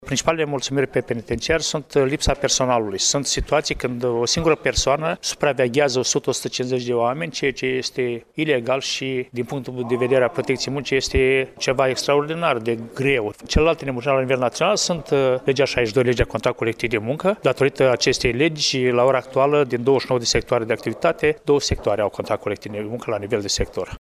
Anunţul a fost făcut, astăzi, în şedinţa Comisiei de Dialog social de la Iaşi.